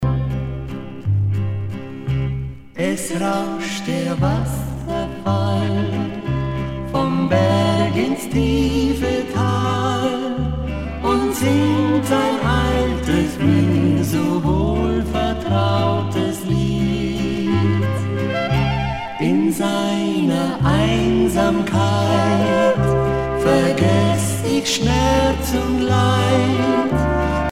valse
Pièce musicale éditée